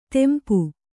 ♪ tempu